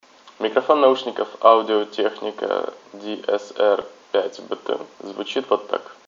6.1 Качество микрофона
Микрофон Audio-Technica ATH-DSR5BT обладает средним качеством записи. Для разговоров хватает, но не более.
• Качество микрофона. Передает голос без помех. Удачное расположение.